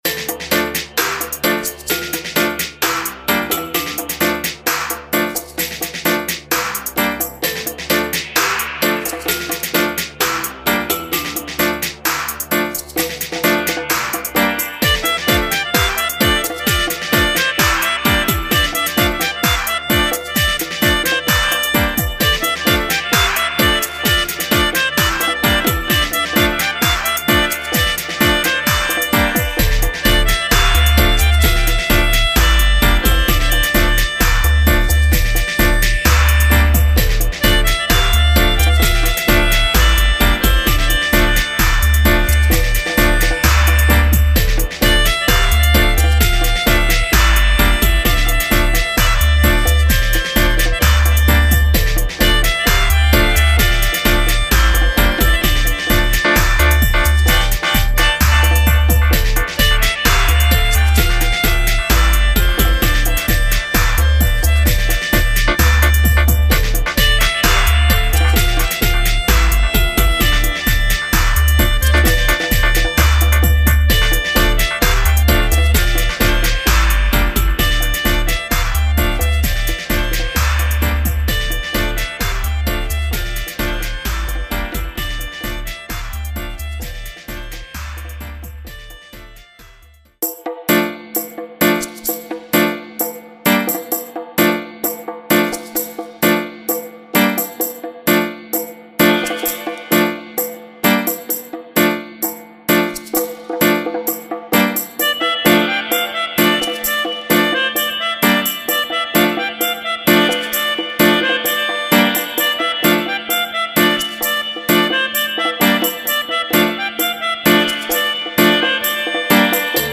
Dubplate pressure, steppers stylee and melodica chant.